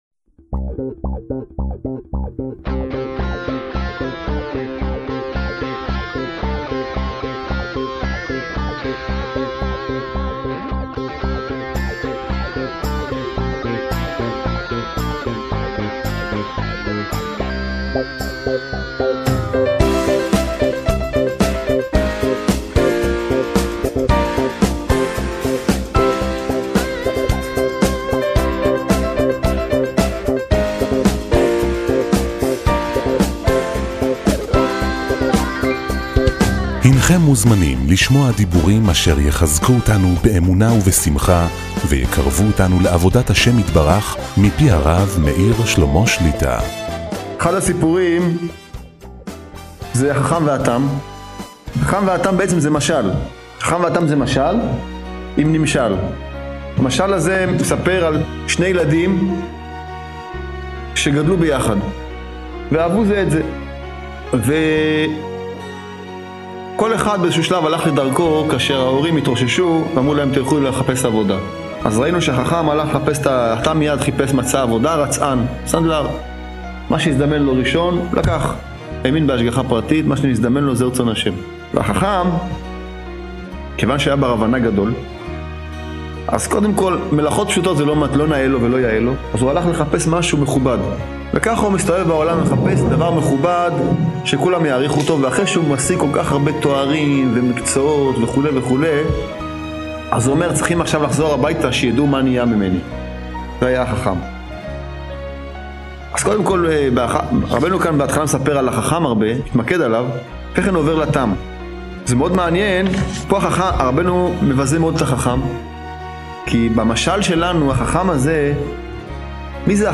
שיעורי שמע